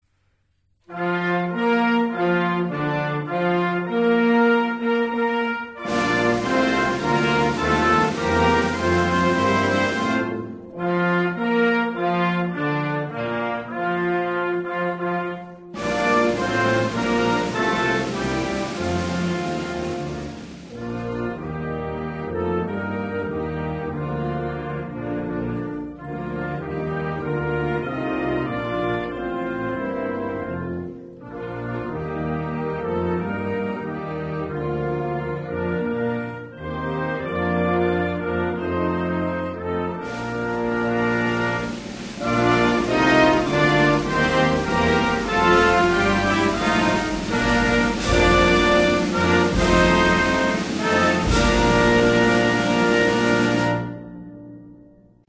15. National anthems (New Zealand  &
AdvanceAustFair.mp3